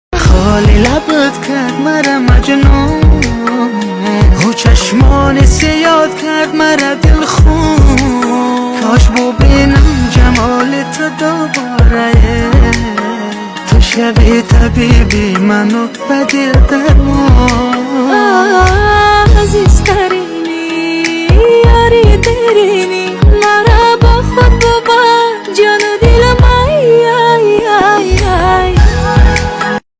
اهنگ افغانی